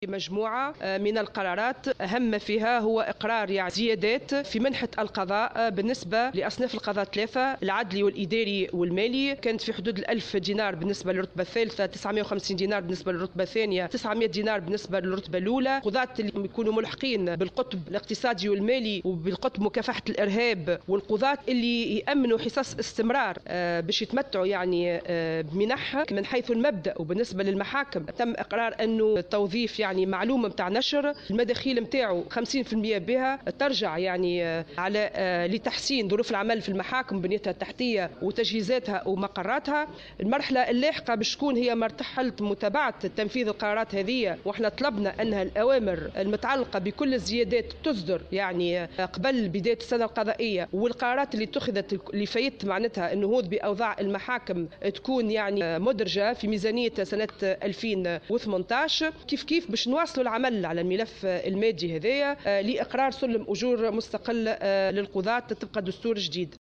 خلال ندوة صحفية عقدتها اليوم الثلاثاء